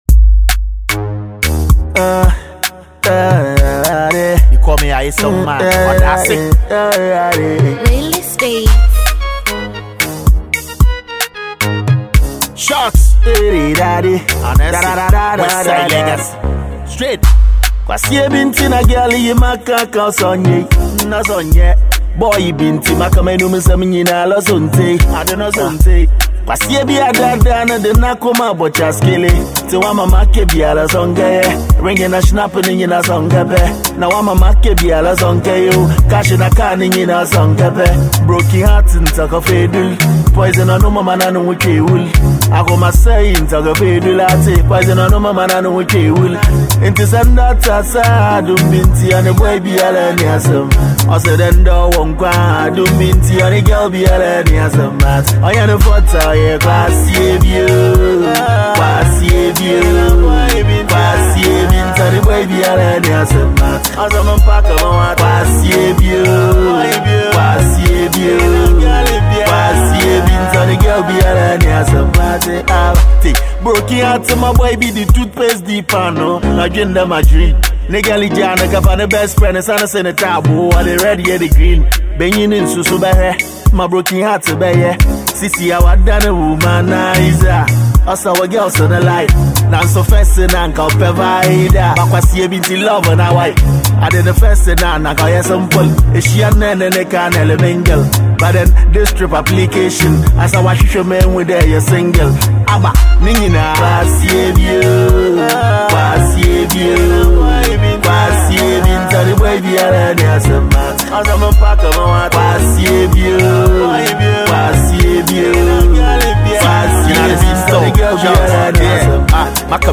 afrobeat song